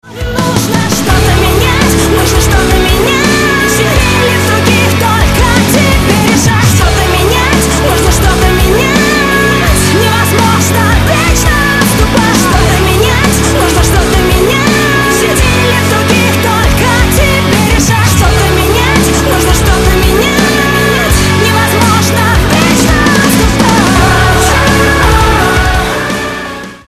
• Качество: 128, Stereo
громкие
женский вокал
Metal
Альтернативный рок